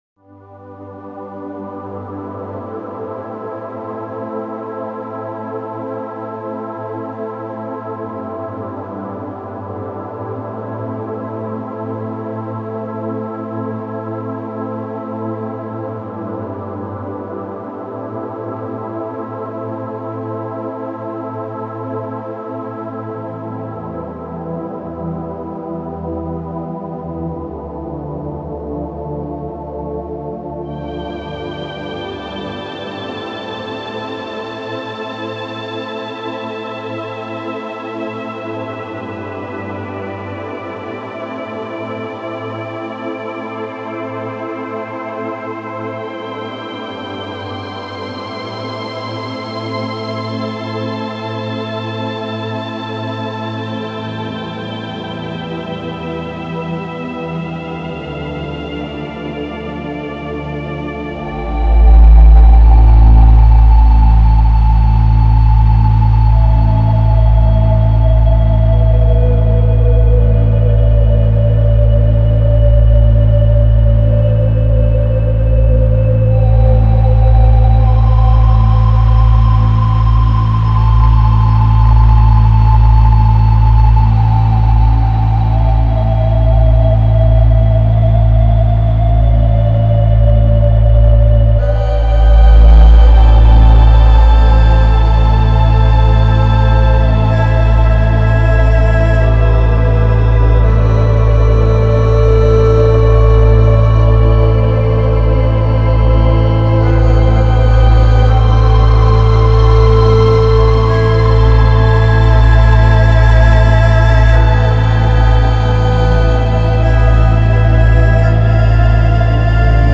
Sentado de manera cómoda, columna erguida para expandir suficientemente el diafragma durante la respiración y déjate llevar por la música y la vibración que experimentarás, al hacer clic en el siguiente audio.